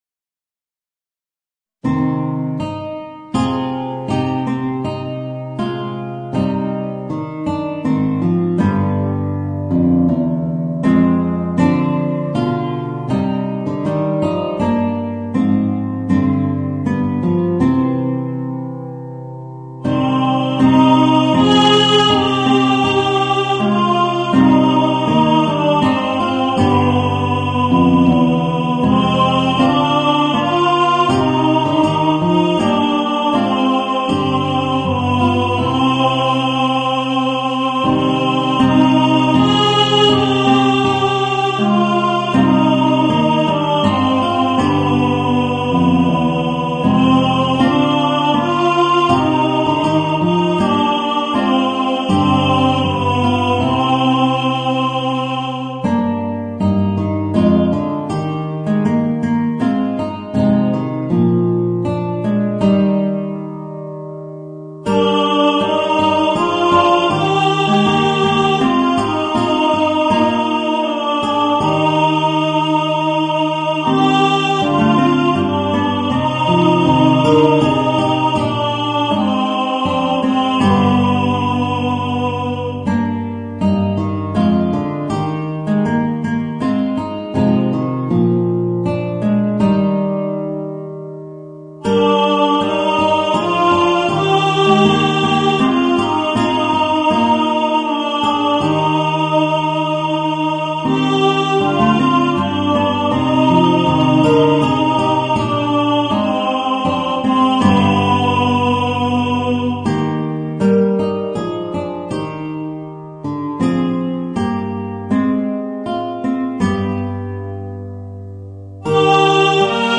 Voicing: Guitar and Tenor